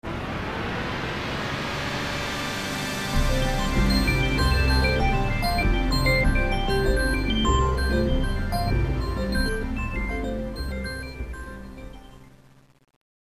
The visitcard of windows is the music, which will played when windows is starting.
Probably the strangest sound. Created for a beta version, not used in the final product.